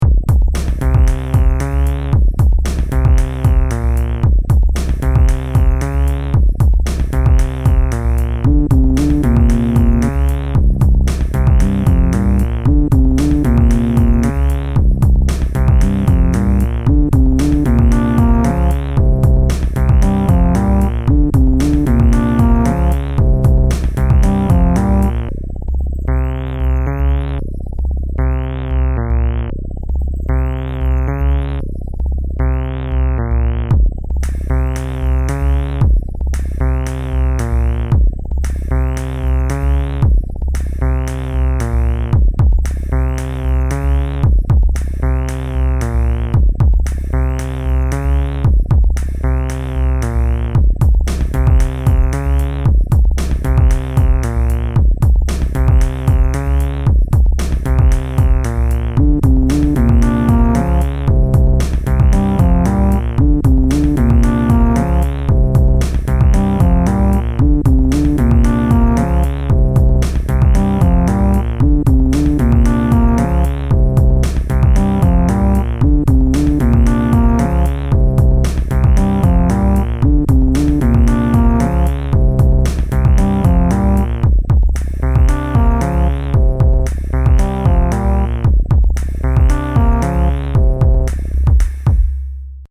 Pieza electro-Drum and Bass